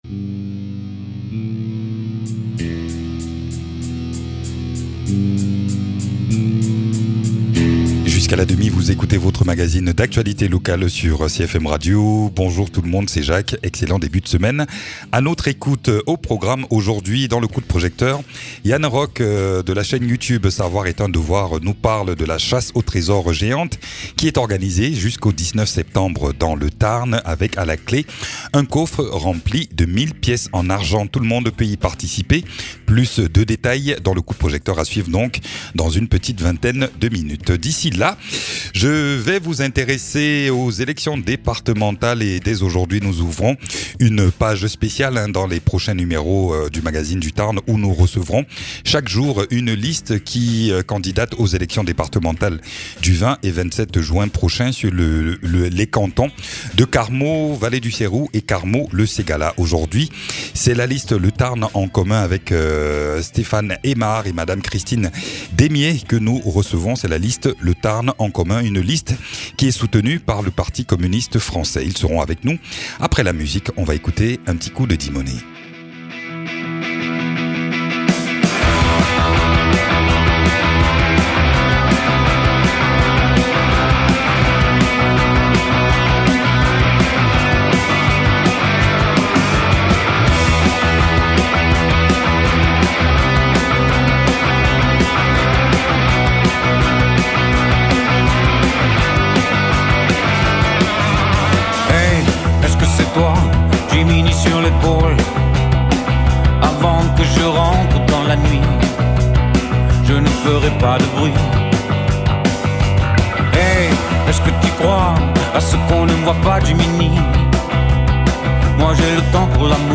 La liste "le Tarn en commun" invité dans ce magazine pour parler de leur candidature aux élections départementales sur le canton Carmaux-1 Le Ségala. Et puis, il est question de la chasse au trésor inédite dans le Tarn organisé par la chaîne youtube "savoir est un devoir".